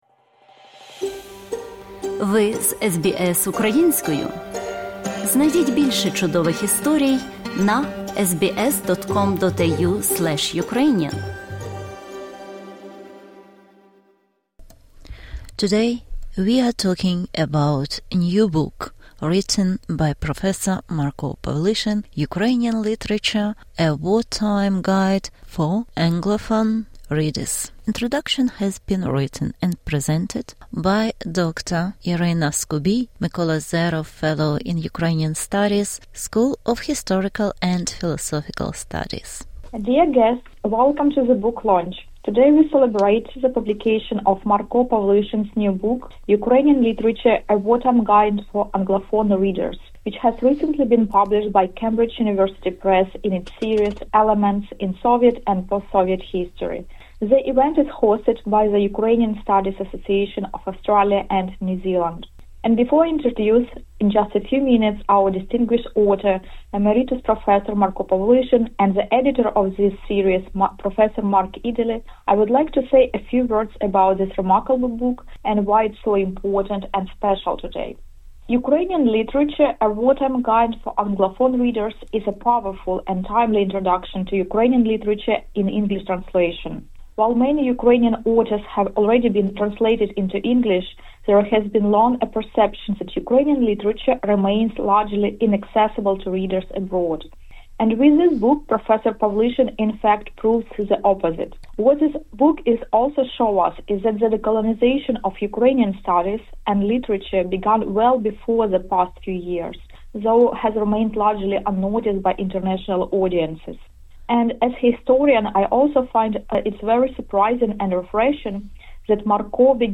Презентація